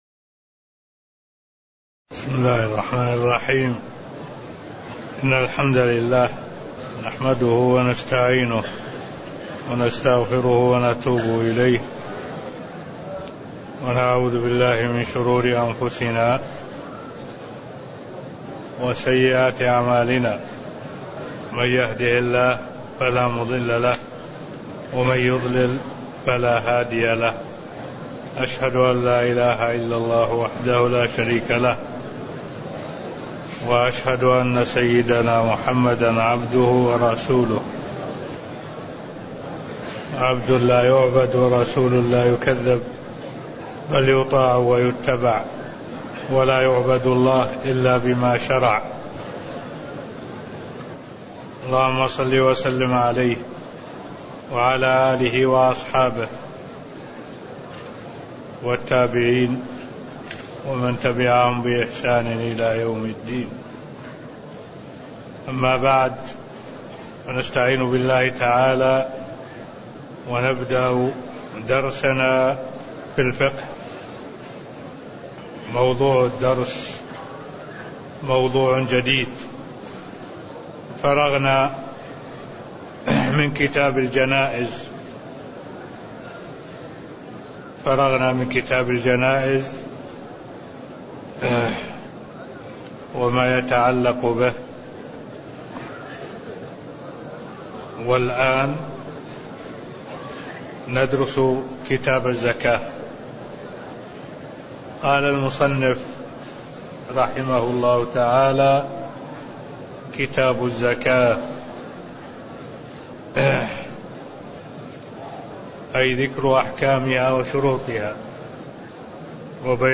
تاريخ النشر ٣٠ ذو الحجة ١٤٢٦ هـ المكان: المسجد النبوي الشيخ: معالي الشيخ الدكتور صالح بن عبد الله العبود معالي الشيخ الدكتور صالح بن عبد الله العبود مقدمة كتاب الزكاة (001)